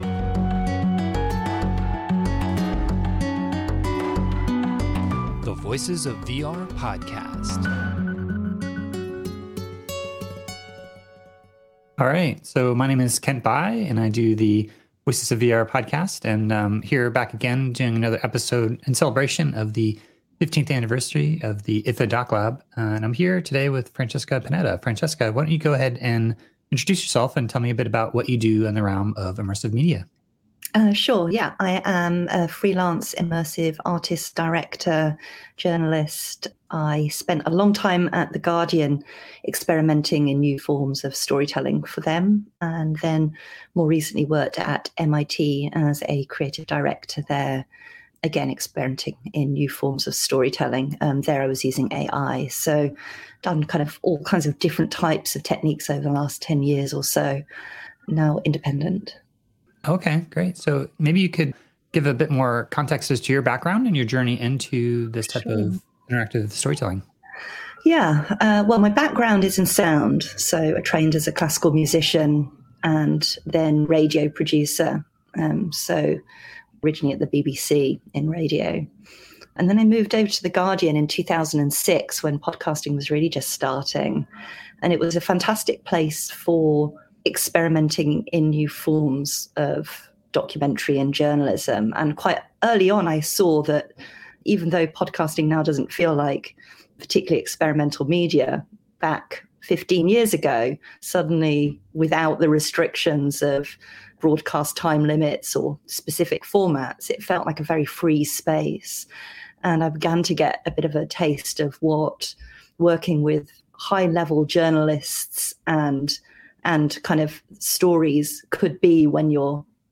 This was recorded on Tuesday, December 14th, 2021 as a part of a collaboration with IDFA’s DocLab to celebrate their 15th year anniversary.